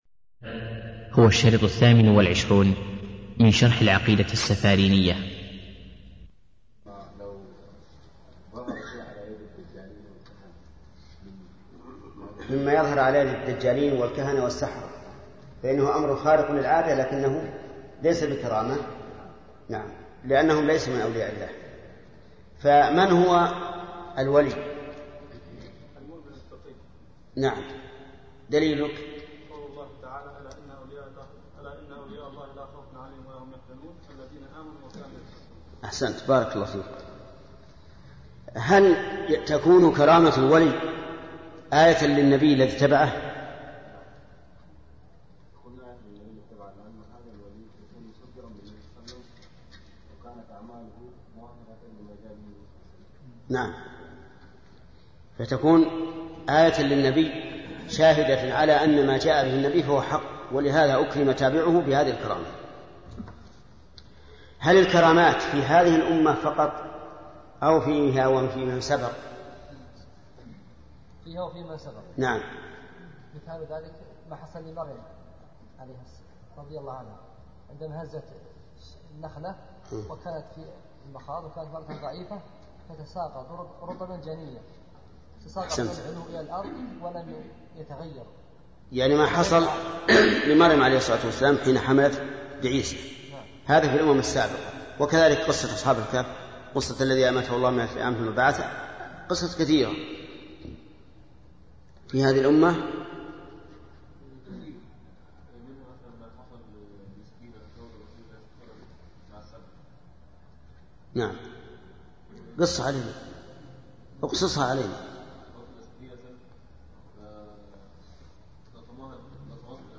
الدرس الثامن والعشرون - فضيلة الشيخ محمد بن صالح العثيمين رحمه الله